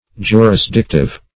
Jurisdictive \Ju`ris*dic"tive\, a.
jurisdictive.mp3